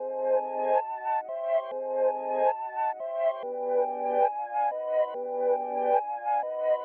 描述：80年代90年代的魔鬼复古,经典环形